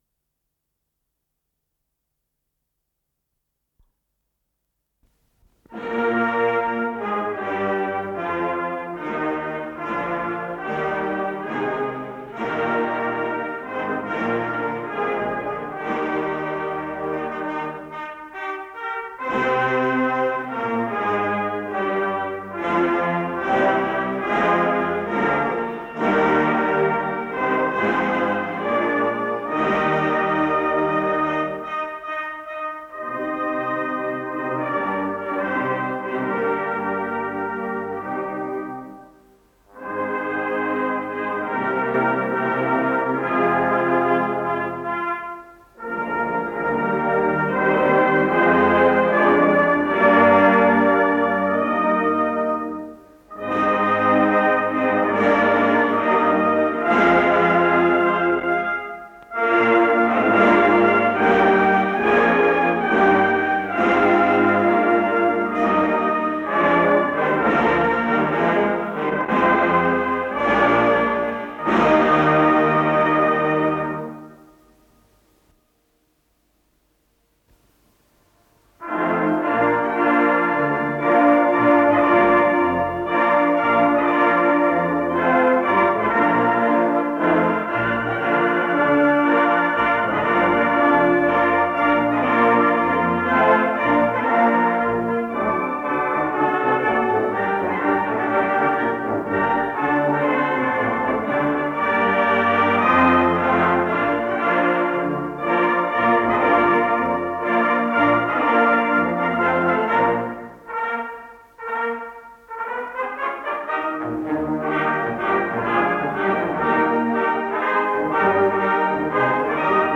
с профессиональной магнитной ленты
Скорость ленты38 см/с
ВариантМоно